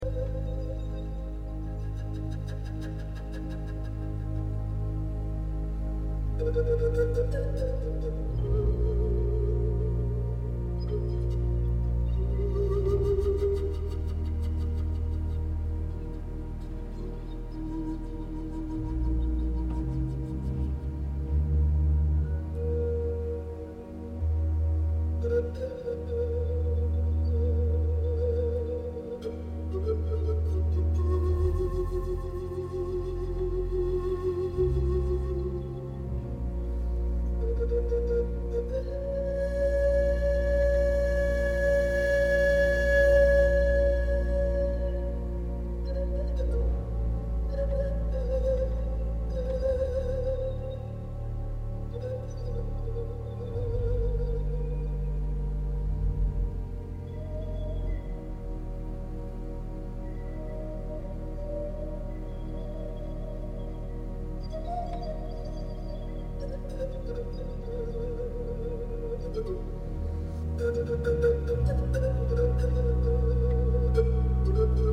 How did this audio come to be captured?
La dinamica risultante è spettacolare!